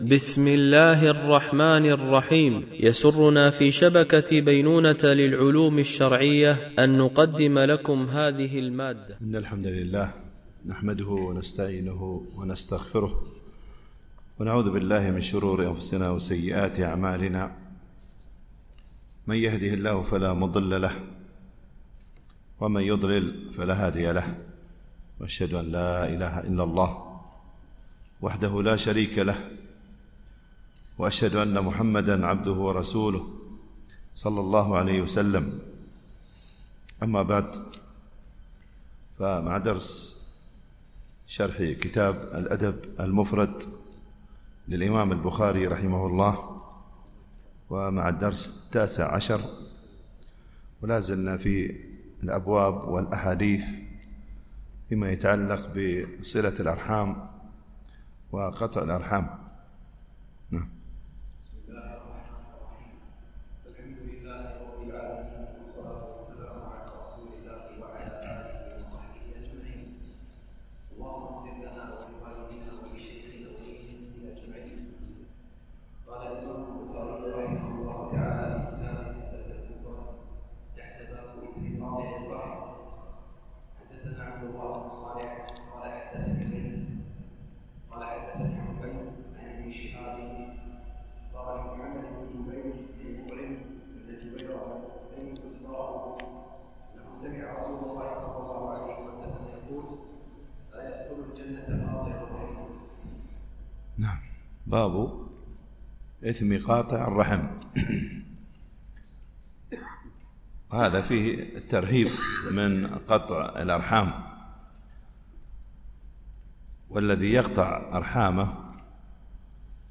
الدرس 19